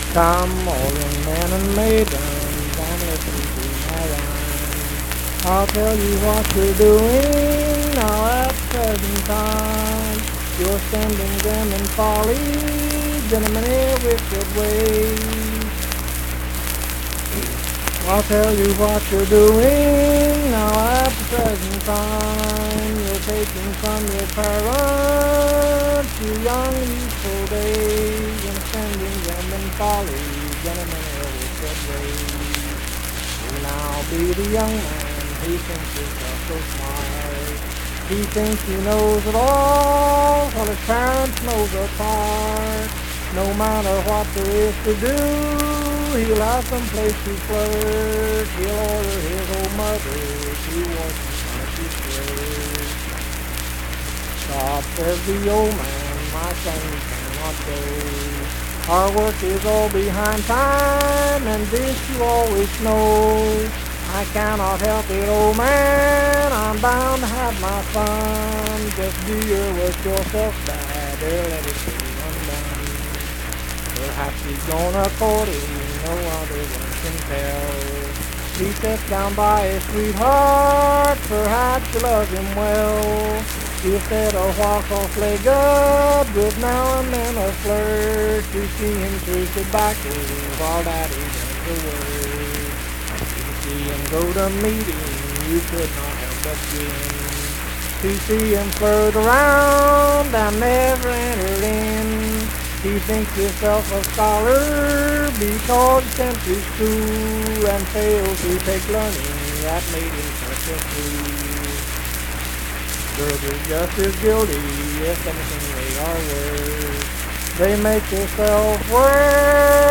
Unaccompanied vocal music
Verse-refrain 7(8).
Voice (sung)
Pleasants County (W. Va.), Saint Marys (W. Va.)